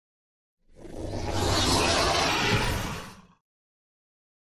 Monster Big Alien - Angry Growl 2